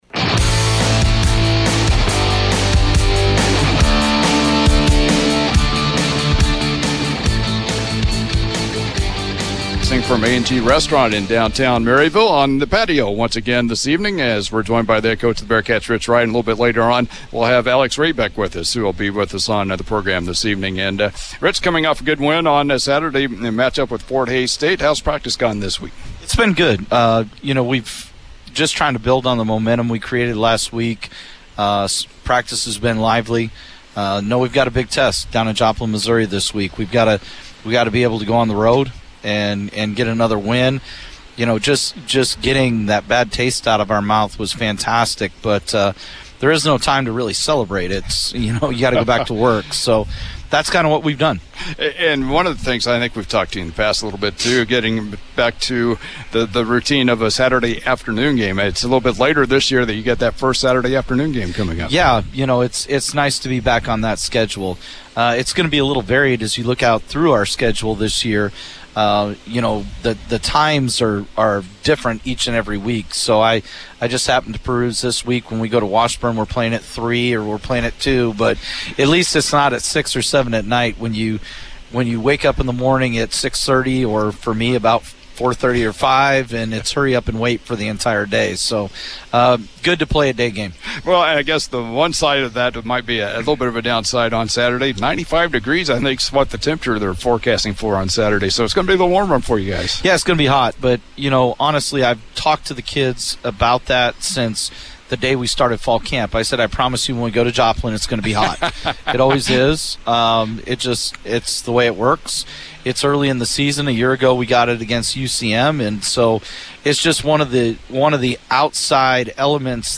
Catch In the Trenches every Thursday at 6:00pm live from A&G Restaurant in downtown Maryville.